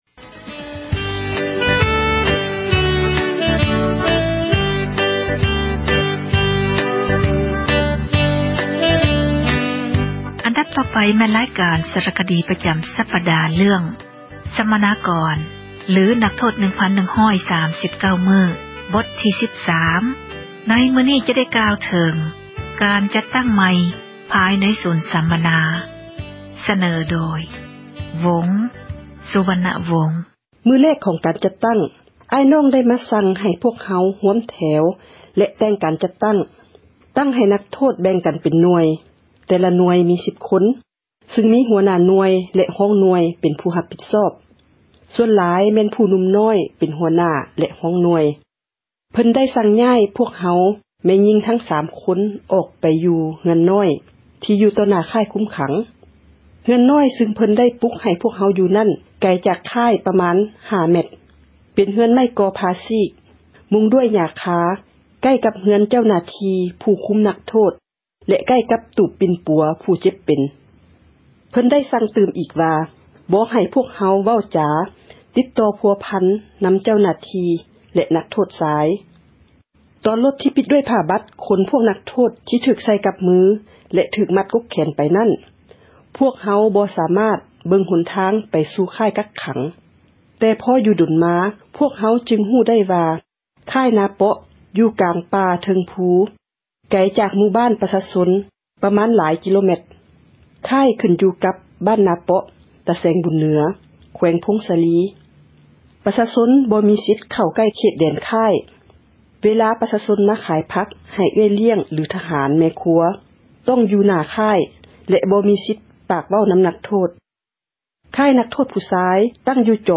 ສາຣະຄະດີ ເຣື້ອງ ສັມມະນາກອນ ຫຼື ນັກໂທດ 1,139 ມື້. ບົດທີ 13 ຈະກ່າວເຖິງ ການຈັດຕັ້ງ ໃໝ່ ພາຍໃນສູນ ສັມມະນາ.